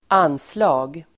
Uttal: [²'an:sla:g]